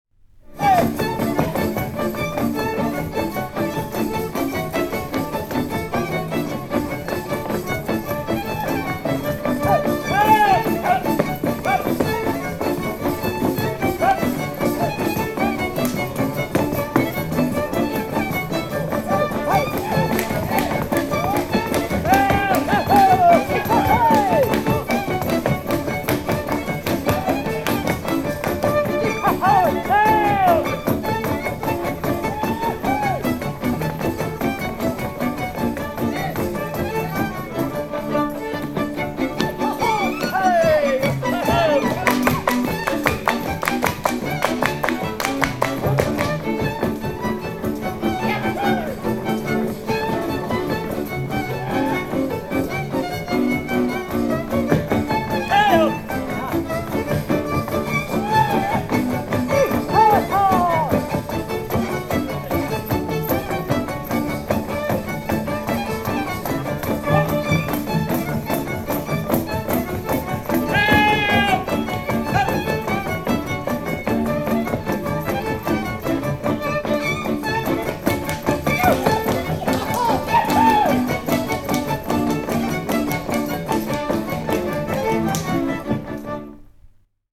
Műfaj Ugrós
Részl.műfaj Dus
Hangszer Zenekar
Helység Szany